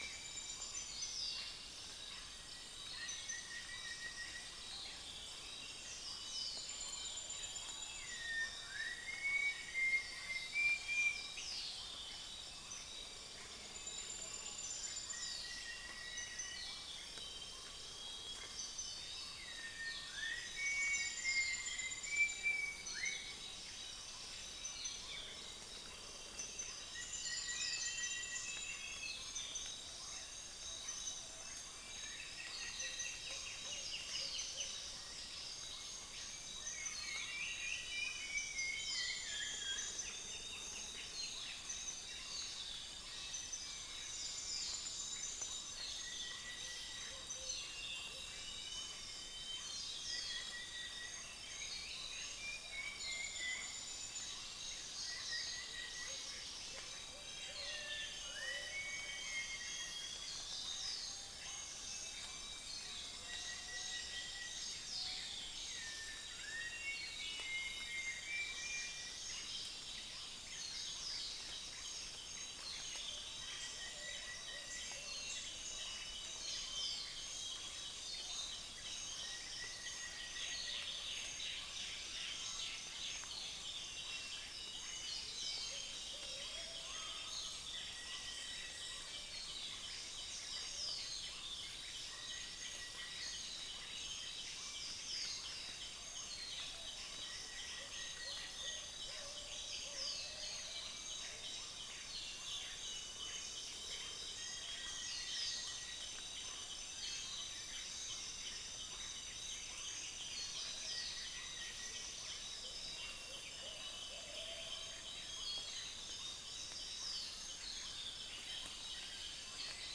SoundEFForTS Upland plots dry season 2013
Stachyris maculata
Pomatorhinus montanus
Trichixos pyrropygus
Cyanoderma rufifrons